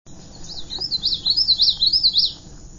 Yellowthroat song, Prime Hook Nat. Wildlife Refuge, Boardwalk Trail, Milford, DE, 8/7/05 (12kb)
Stokes:  Song sounds like "witchity-witchity-witchity" or "your money, your money, your money."
yellowthroat_song_868.wav